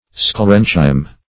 Sclerenchyme \Scle*ren"chyme\, n.